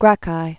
(grăkī)